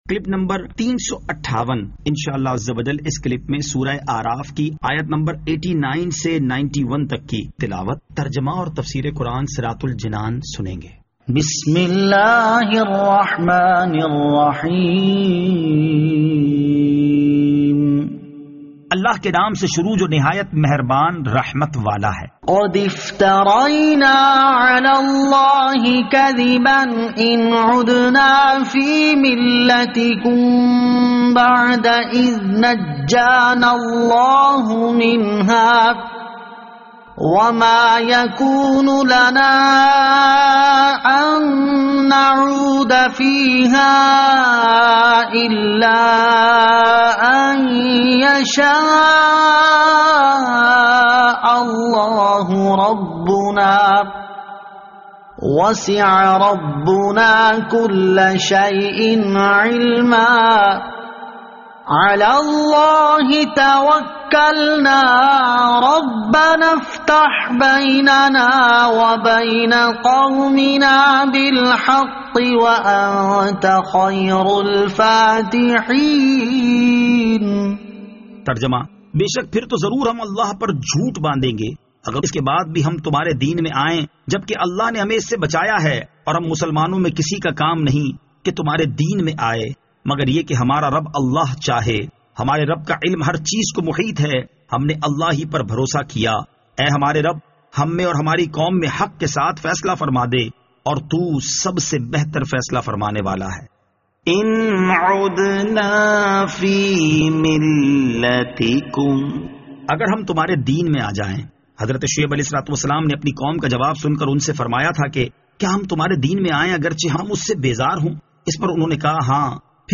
Surah Al-A'raf Ayat 89 To 91 Tilawat , Tarjama , Tafseer